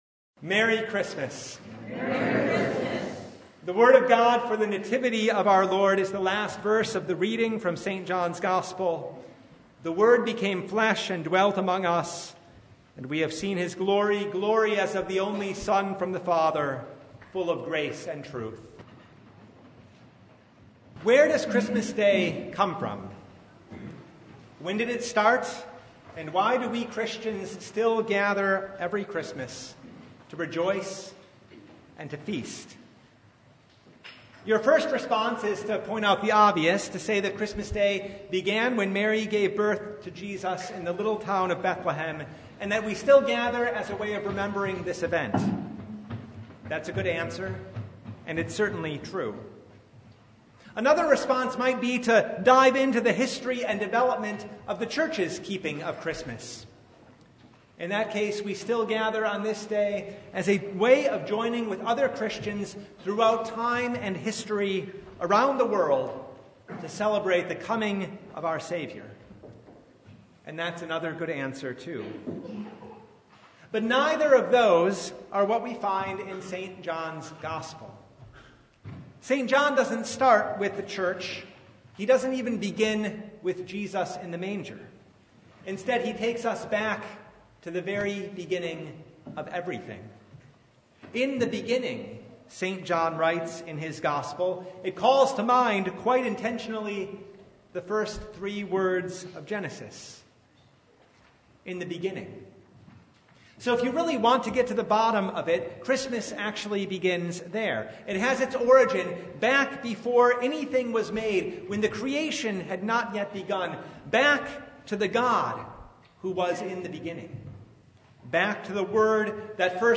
Service Type: Christmas Day
Topics: Sermon Only « Ready or Not .